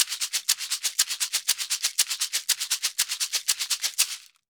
Maracas_ ST 120_3.wav